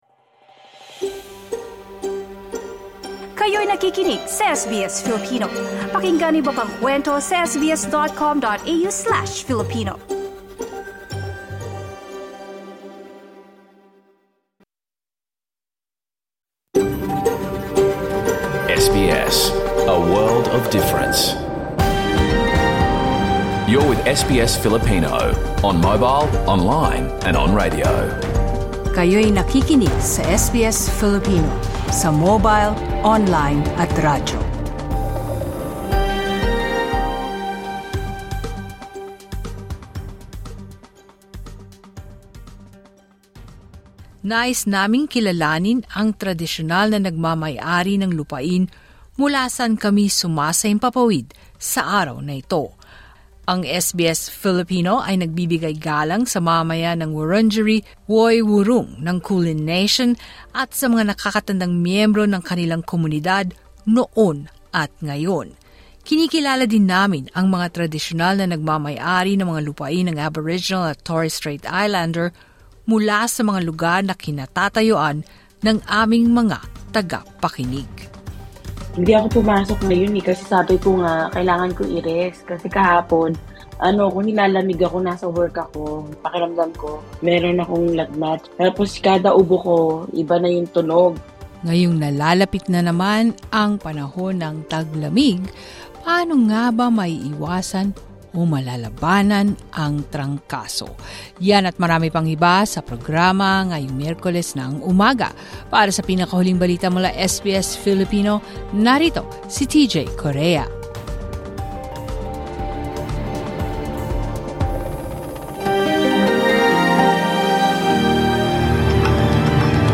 Radio Program